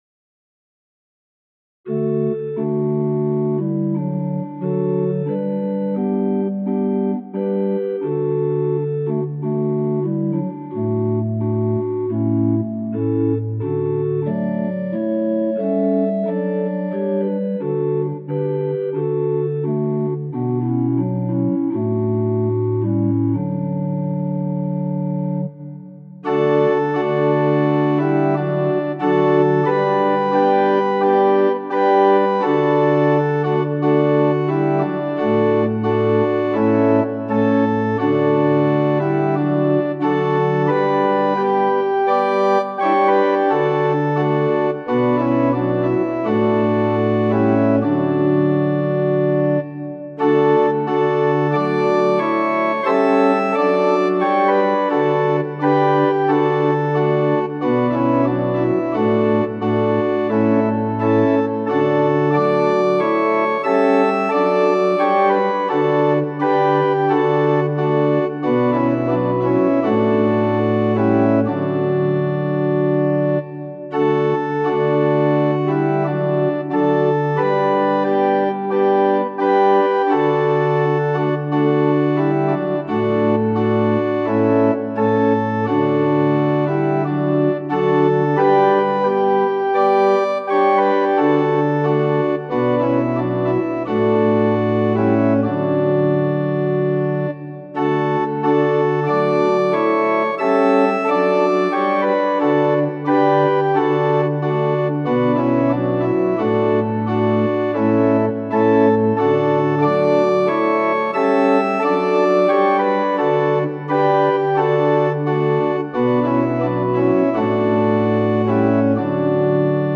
♪賛美用オルガン伴奏音源：
・柔らかい音色)部分は前奏です
・はっきりした音色になったら歌い始めます
・節により音色が変わる場合があります
・間奏は含まれていません
Tonality = D Pitch = 440
Temperament = Equal